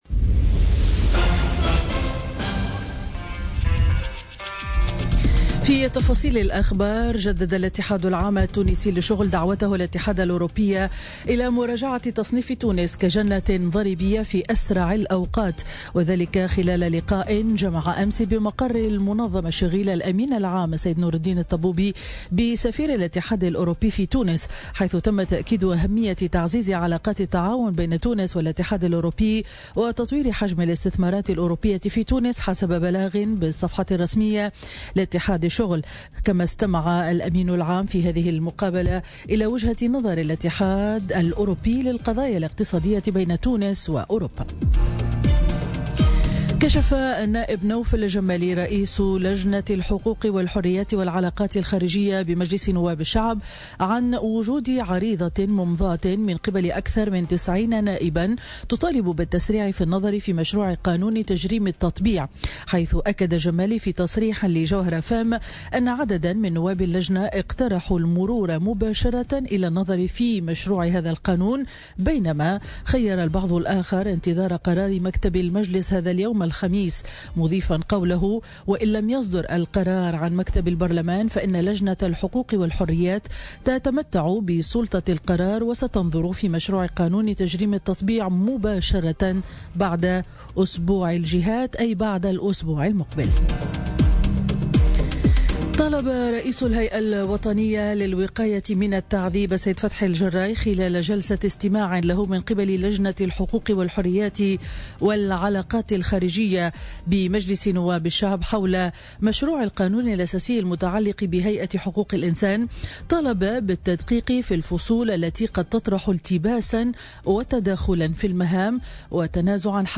نشرة أخبار السابعة صباحا ليوم الخميس 21 ديسمبر 2017